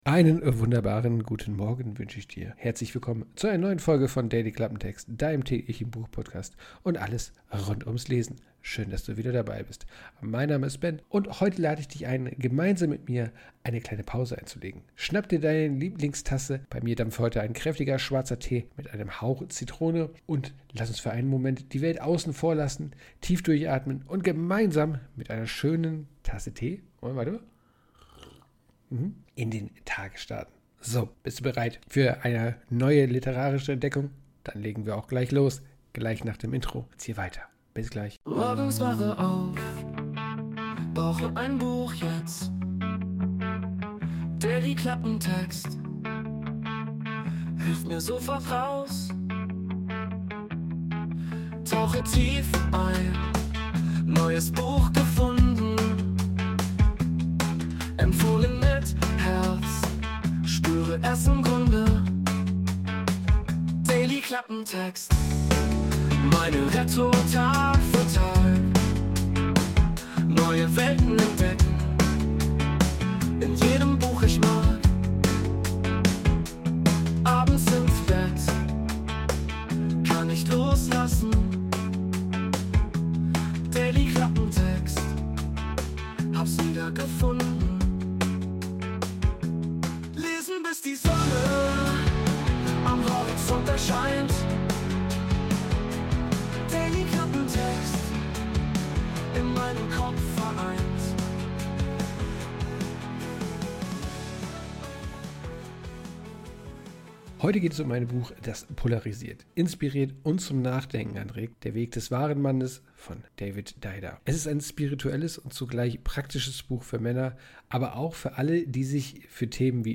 Peaceful Cinematic (No Copyright Music)
Intromusik: Wurde mit der KI Suno erstellt.